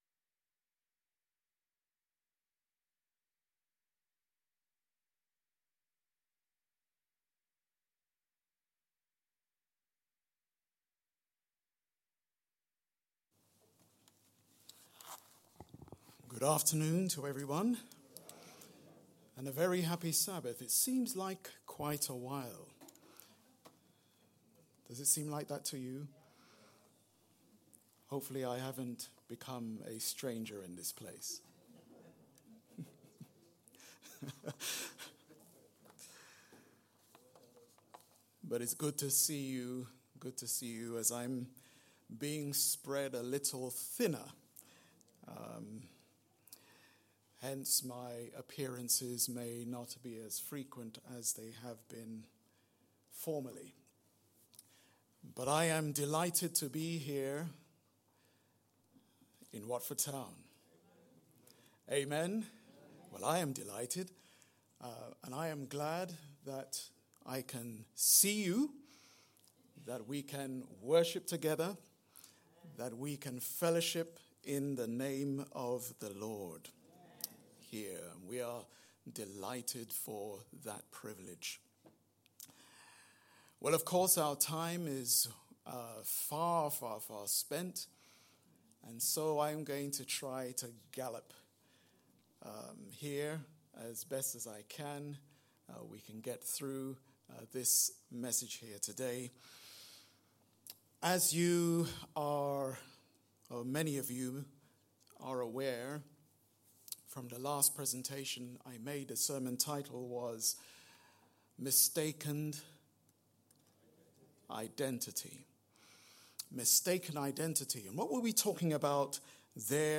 Family Service Recordings Download Other files in this entry